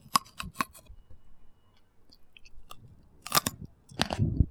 • eating a white grape crackling.wav
Experience the distinct, visceral sound of a grape being crushed between teeth.
eating_a_white_grape_crackling_9u8.wav